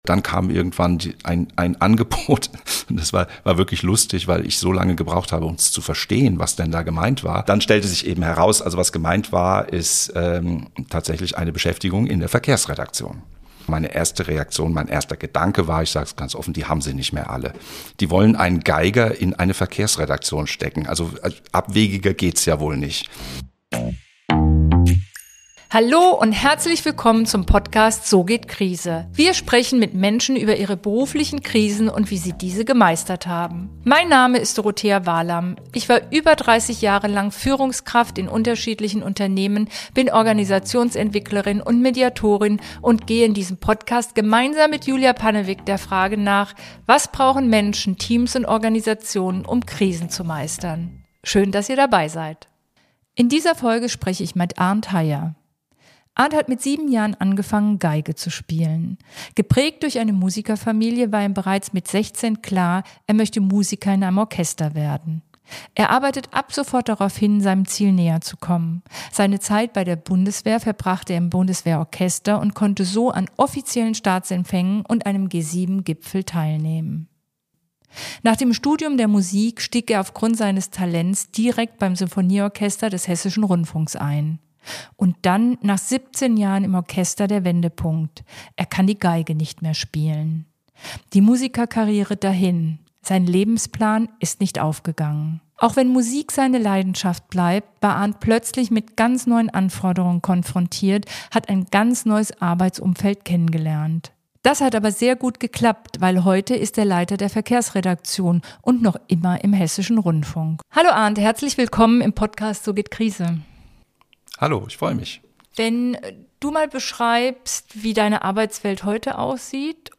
Ein Gespräch über Abschiede, Wandel und neue Chancen. +++ Wir sprechen in diesem Podcast mit Menschen, die in einer schwierigen beruflichen Situation waren und erfahren, wie sie die Krise gemeistert haben, was sie geprägt hat und wie unterschiedlich die Unternehmen in Krisenzeiten reagieren.